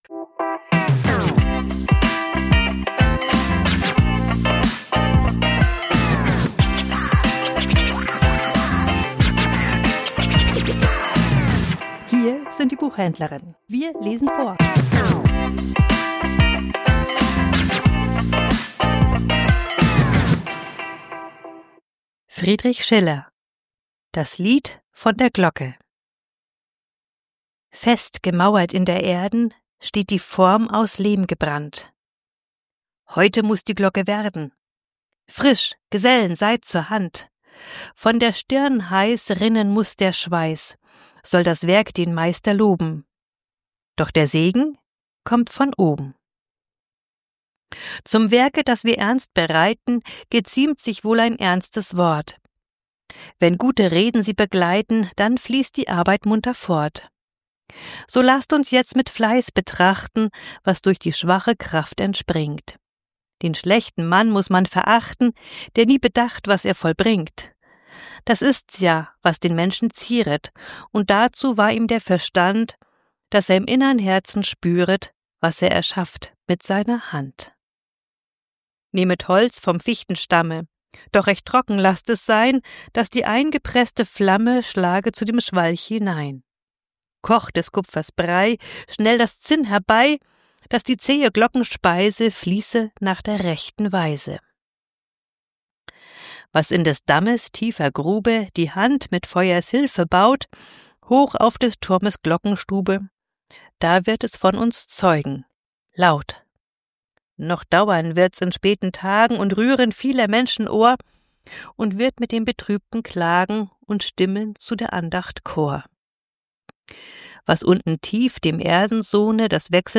Vorgelesen: Das Lied der Glocke ~ Die Buchhändlerinnen Podcast